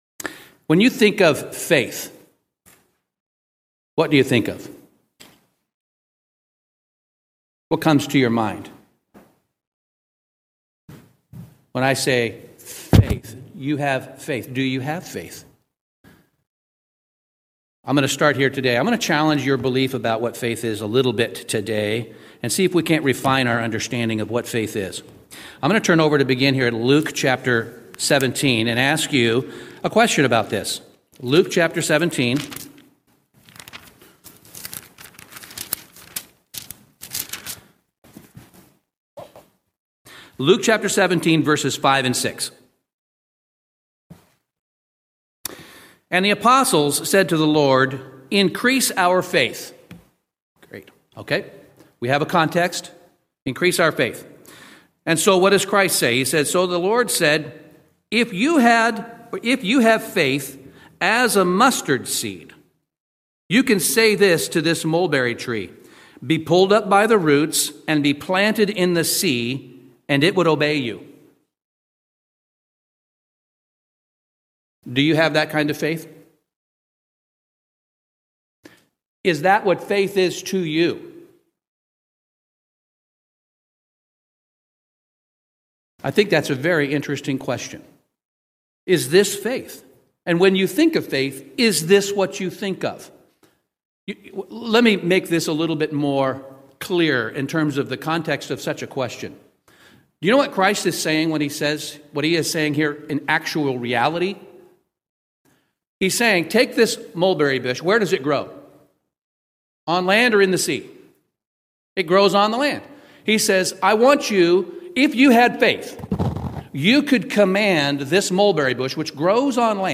This message explores the meaning of everyday, real, living faith and how to know you have it!